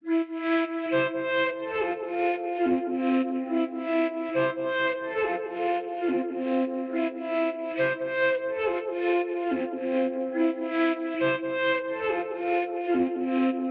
Cloakaine_Flute.wav